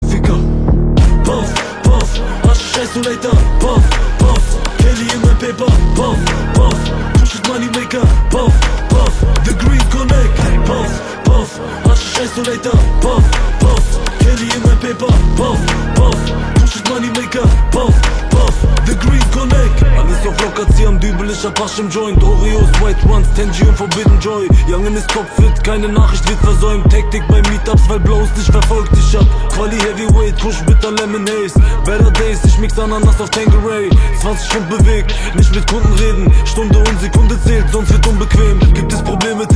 Kategorien Rap/Hip Hop